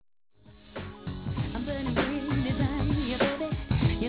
vocals
Modern pop doesn't get any better- crafted than this.